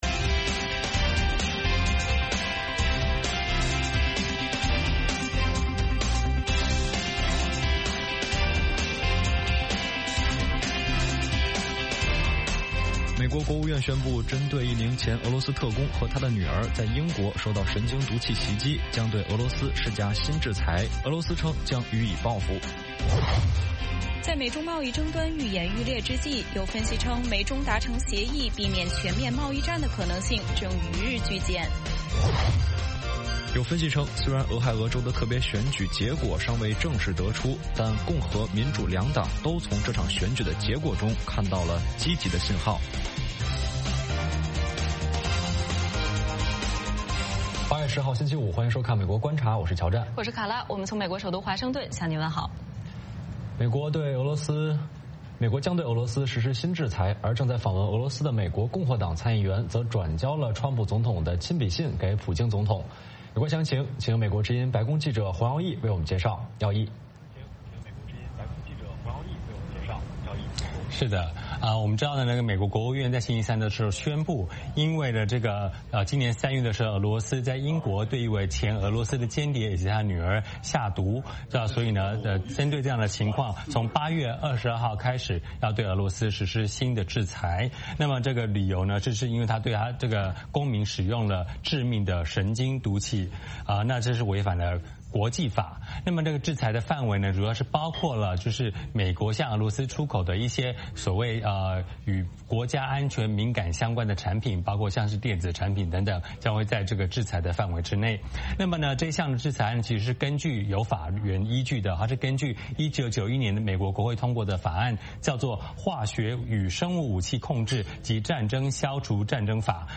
美国之音中文广播于北京时间早上8－9点重播《VOA卫视》节目(电视、广播同步播出)。
“VOA卫视 美国观察”掌握美国最重要的消息，深入解读美国选举，政治，经济，外交，人文，美中关系等全方位话题。节目邀请重量级嘉宾参与讨论。